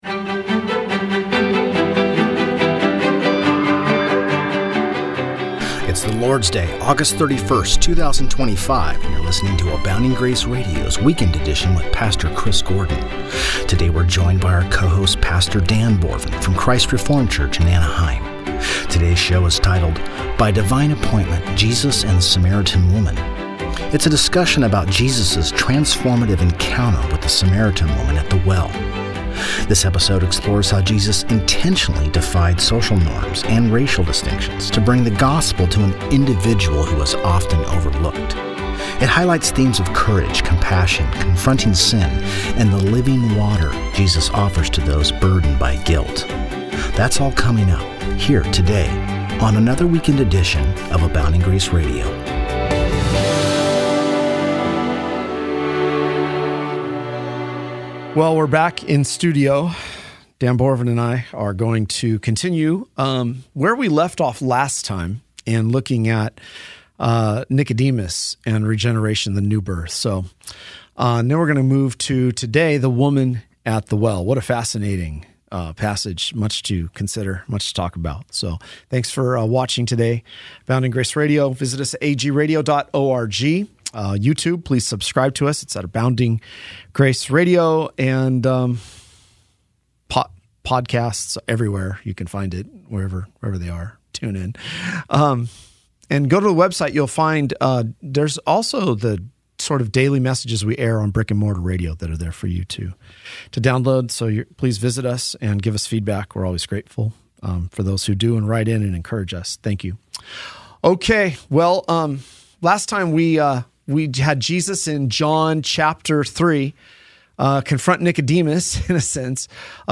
Ultimately, this conversation emphasizes that the gospel is for the entire world, for both Jew and Gentile.